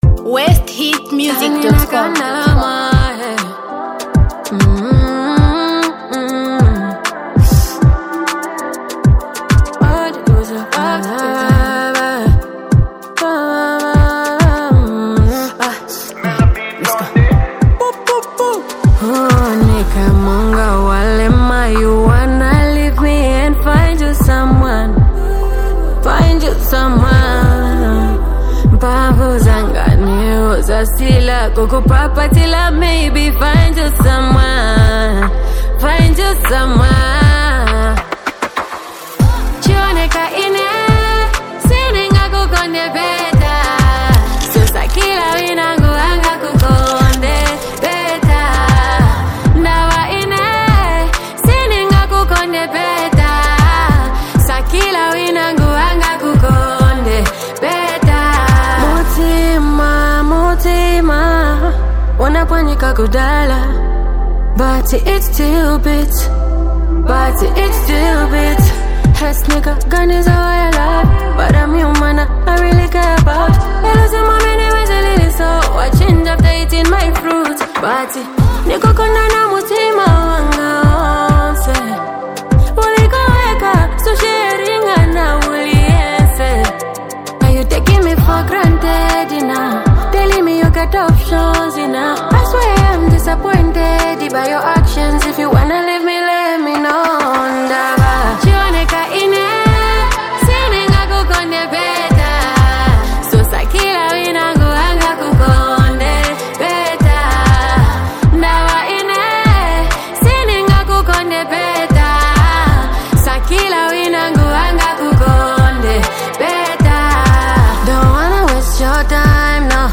Zambia Music